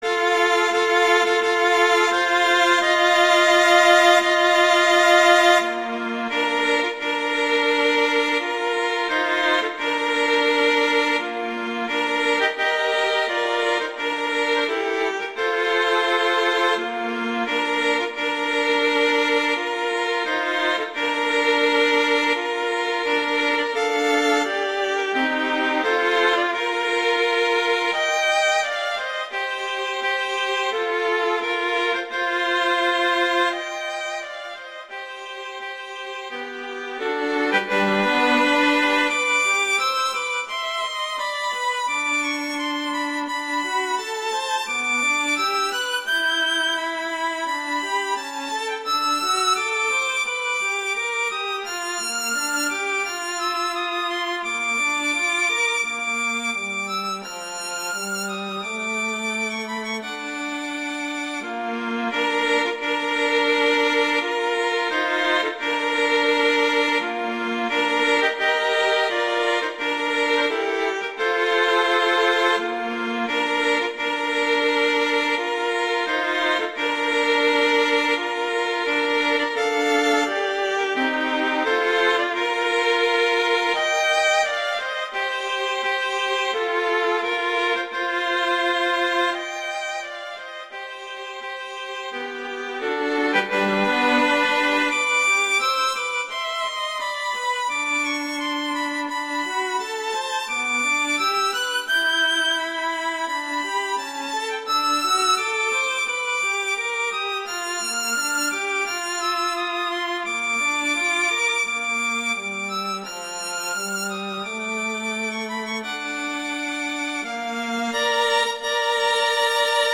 arrangements for violin and viola
wedding, traditional, classical, festival, love, french